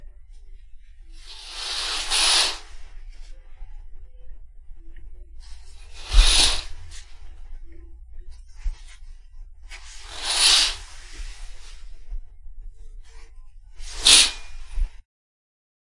难听的声音 " 窗帘
描述：用变焦H6录制。以不同方式打开和关闭窗帘。